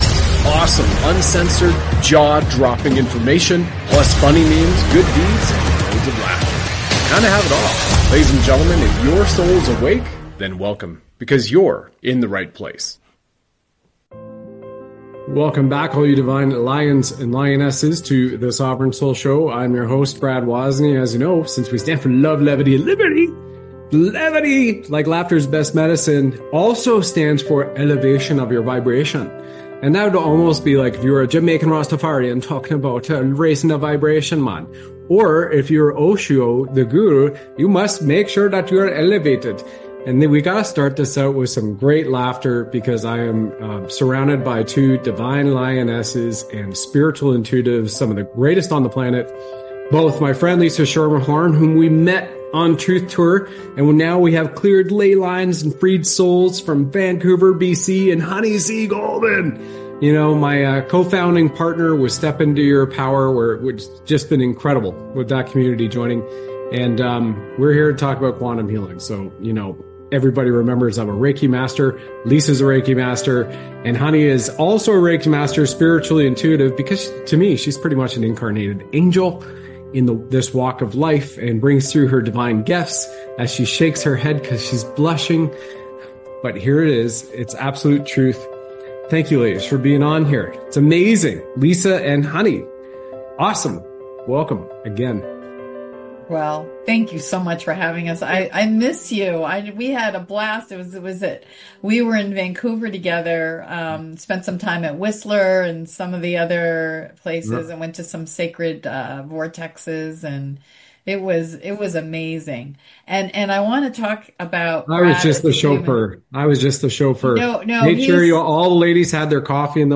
The episode features two guests